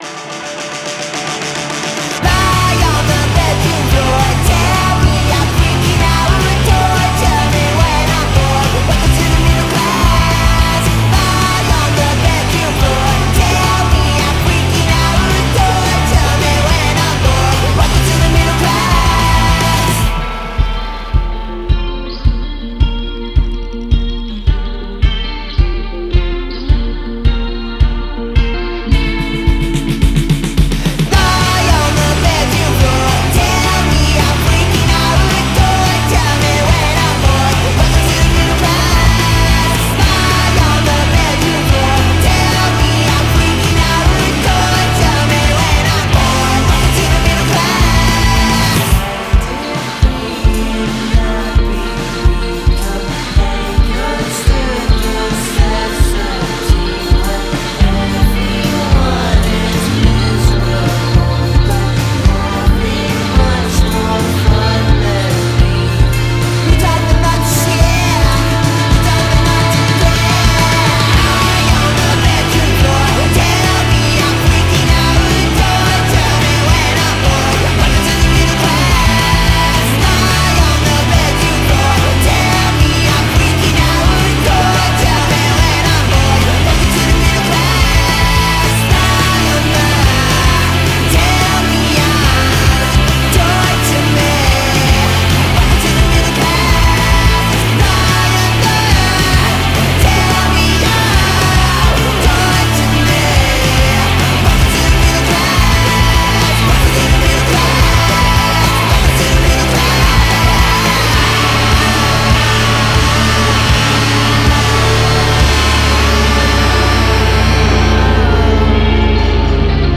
manic poppiness